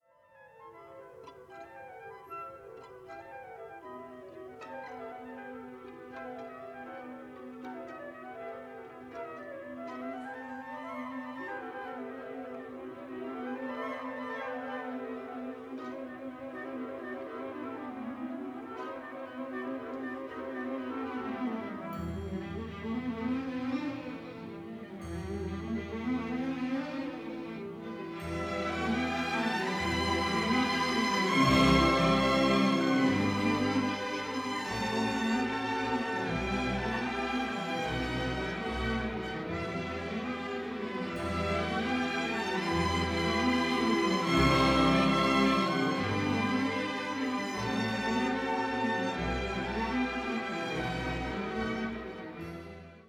a stereo recording